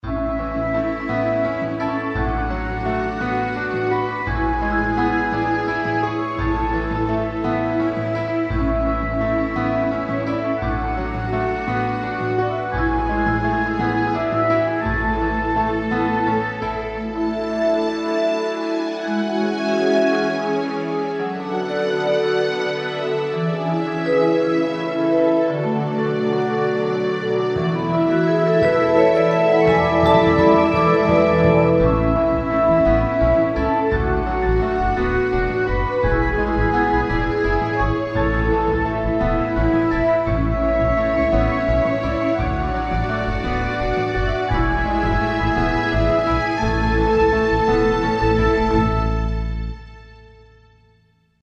Vals lento.
vals
ternario
armonioso
etéreo
lento
mayor
sintetizador
suave
Sonidos: Música